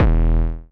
TM88 FartDist808.wav